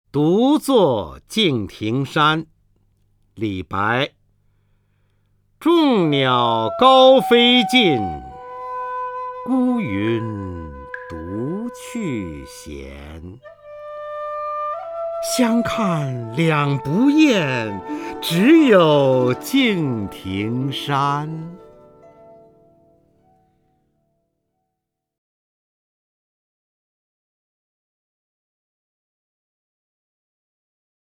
方明朗诵：《独坐敬亭山》(（唐）李白) （唐）李白 名家朗诵欣赏方明 语文PLUS